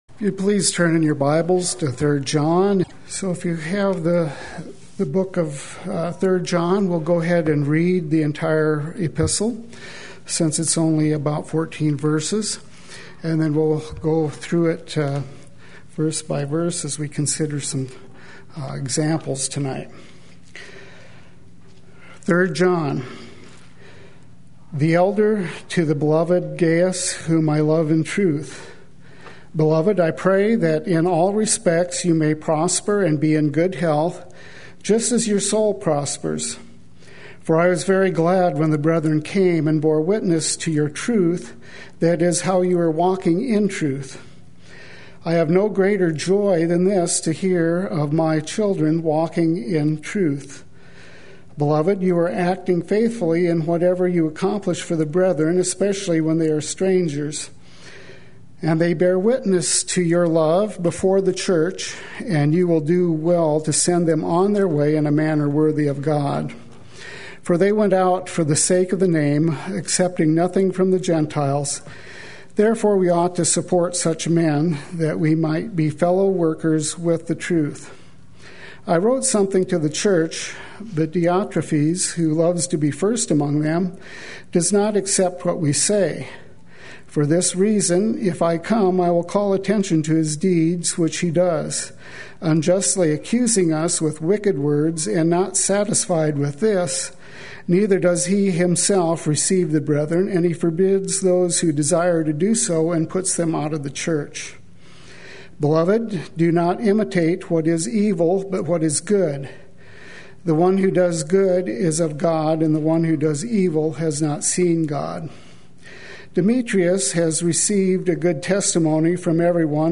Play Sermon Get HCF Teaching Automatically.
Four Examples from 3rd John Wednesday Worship